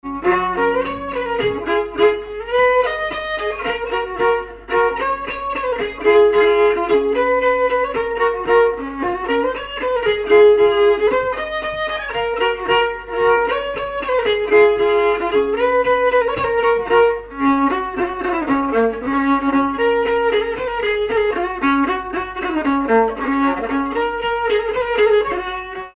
Marche
danse : marche
circonstance : bal, dancerie
Pièce musicale inédite